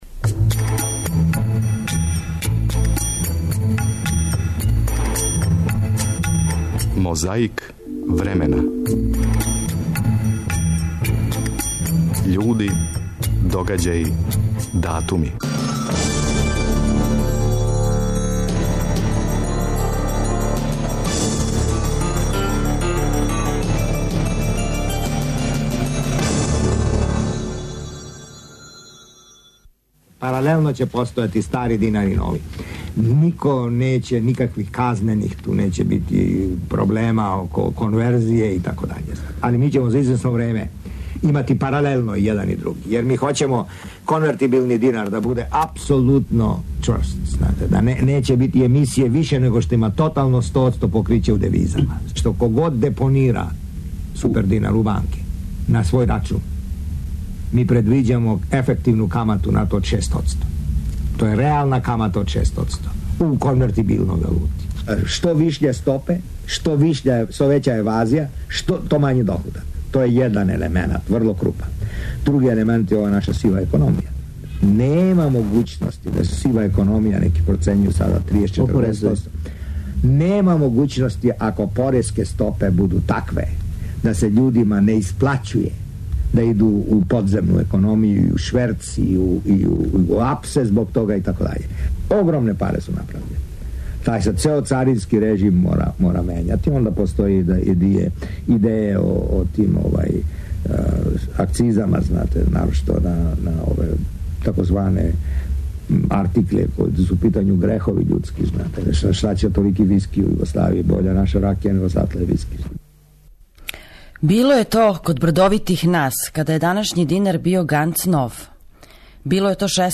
У 'Мозаику времена' слушамо тонске записе Радио Београда који су некада били вести, а сада су архивски материјал. Међу њима, ове суботе, ето и говора Драгослава Аврамовића из 1994.
Тако ћемо освежити сећања, на пример, на интервју из јануара 1991. који је Војислав Коштуница, тада председник СРЈ (када је у свету и код нас сматран реформатором), дао телевизији Би-Би-Си.